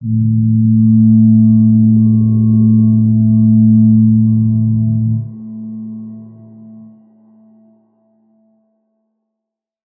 G_Crystal-A3-f.wav